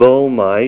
Help on Name Pronunciation: Name Pronunciation: Boehmite